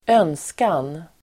Ladda ner uttalet
önskan substantiv, wish Uttal: [²'ön:skan] Böjningar: önskan, önskningar Synonymer: begär, hopp, längtan, vilja, önskemål, önskning Definition: det som man önskar Exempel: få en önskan uppfylld (have a wish come true)